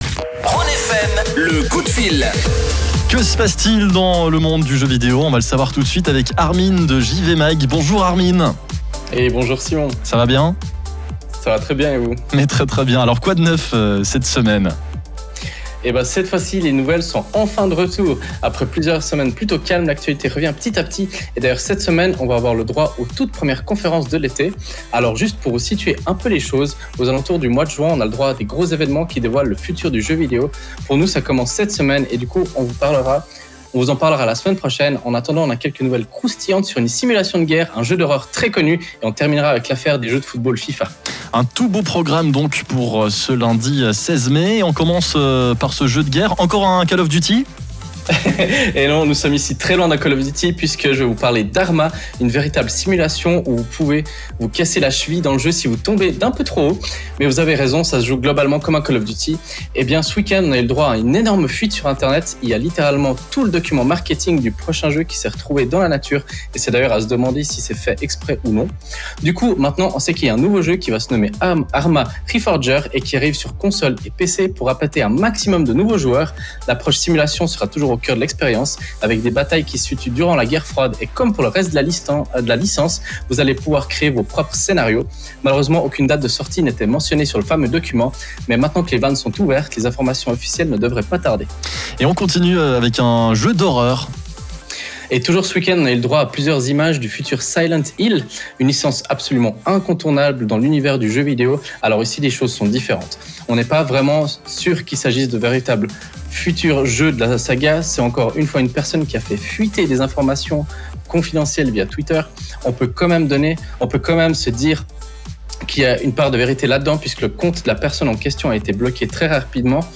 Comme tous les lundis, nous vous proposons notre chronique gaming sur la radio Rhône FM ! Aujourd’hui on en profite pour parler de l’énorme fuite au sujet du futur jeu ARMA, de Silent Hill, ou encore de l’affaire FIFA et Electronic Arts.